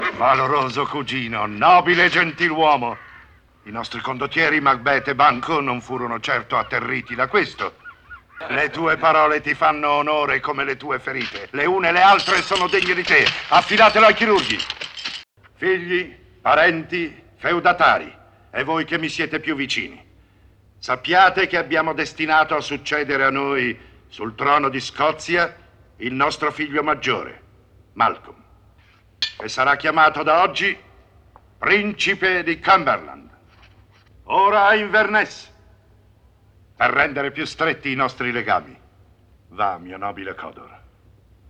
nel film "Macbeth"(1971), in cui doppia Nicholas Selby.